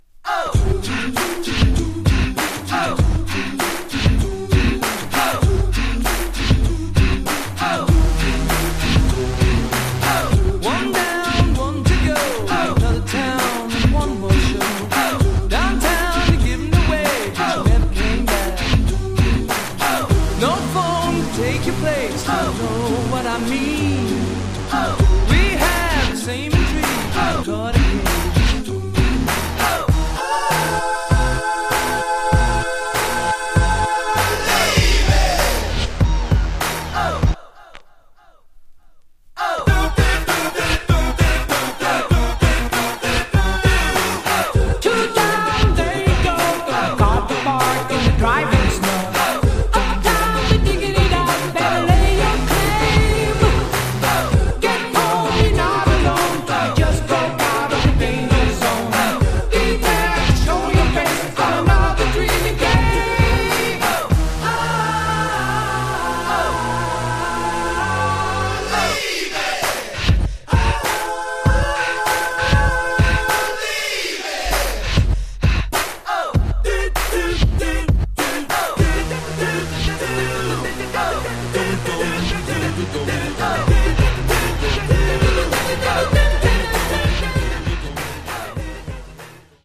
99 bpm
Clean Version